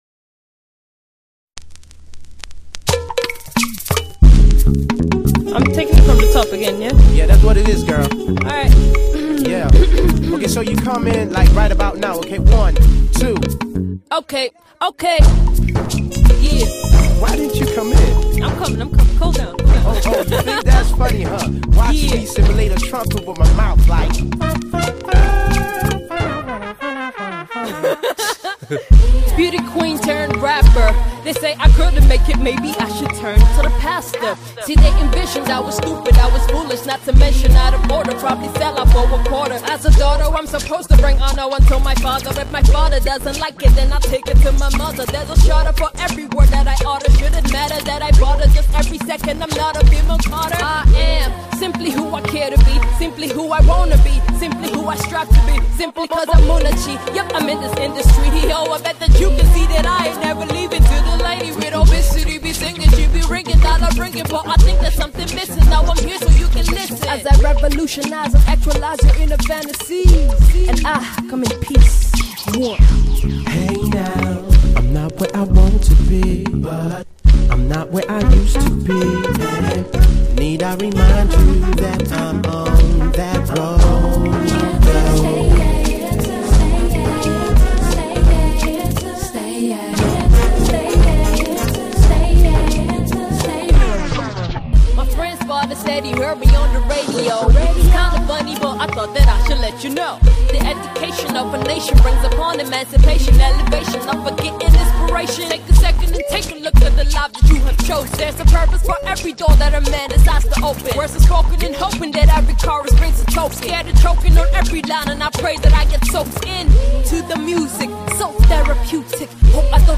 is a low tempo laid back track